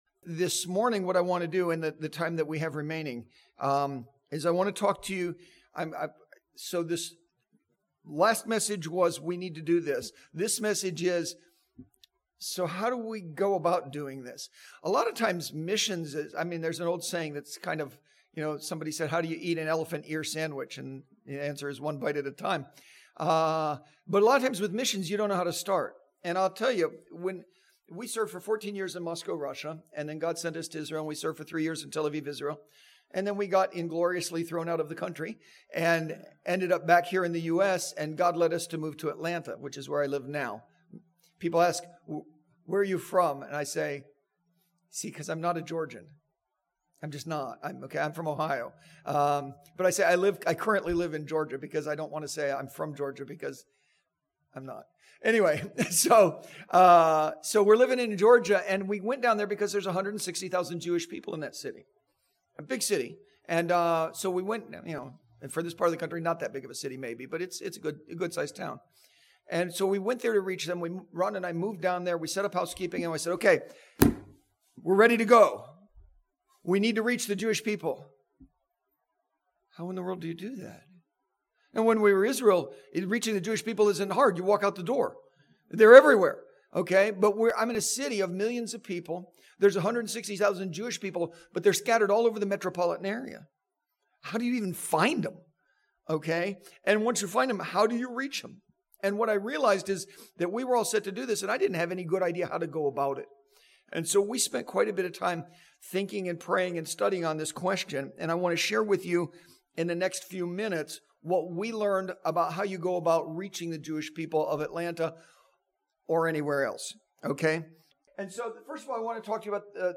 This Sunday School lesson covers effective strategy for Jewish evangelism that introduces a new paradigm for evangelism.